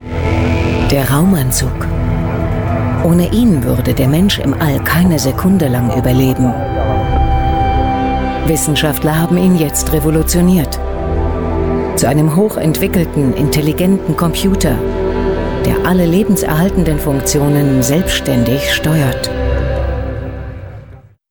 Lektor